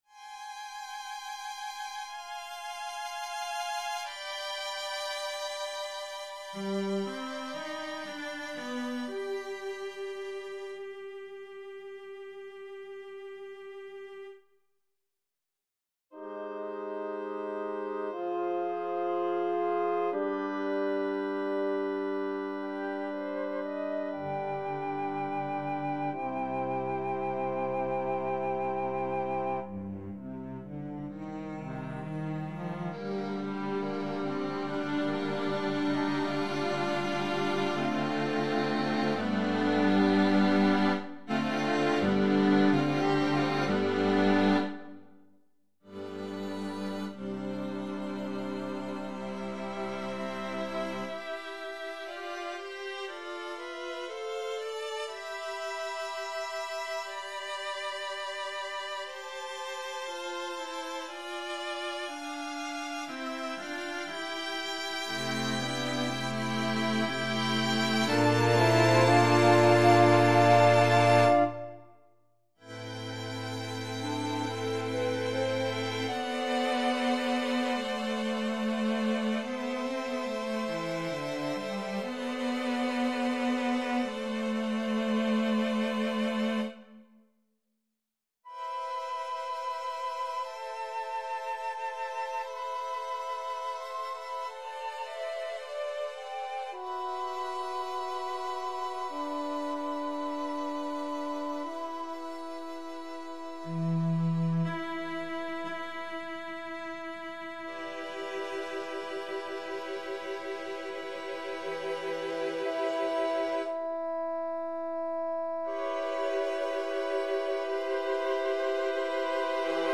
Introspection #1 - Chamber Music - Young Composers Music Forum